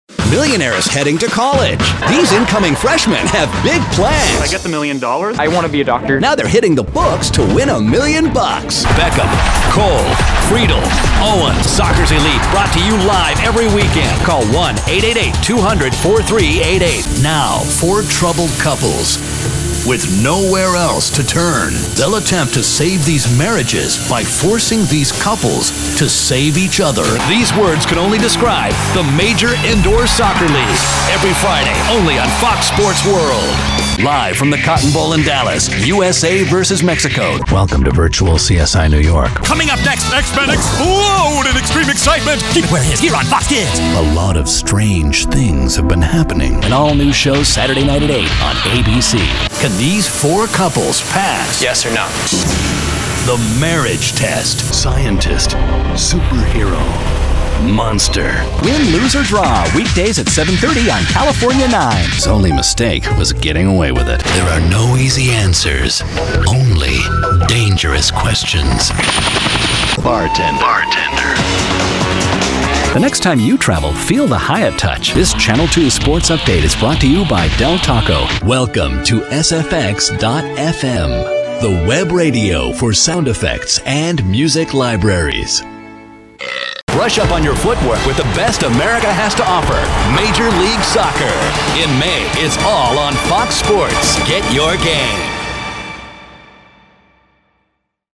standard us
promos